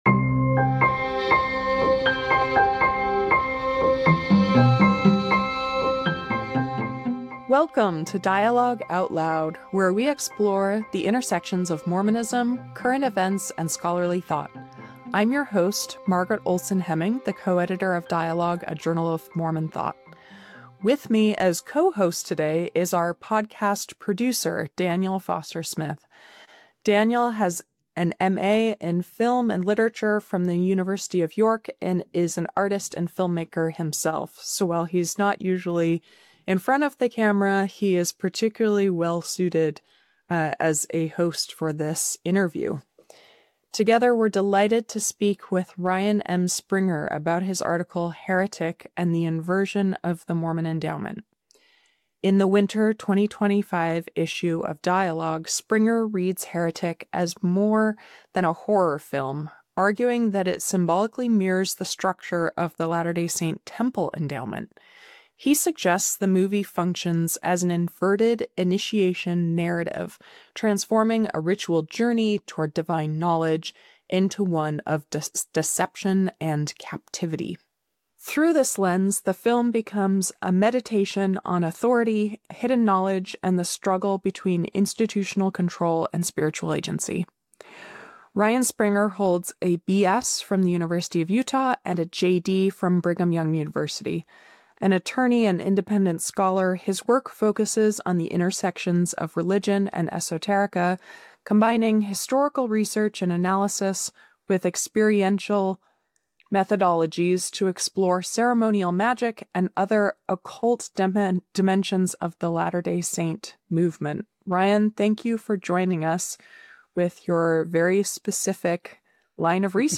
Reading Heretic Through Mormon Rituals: A Conversation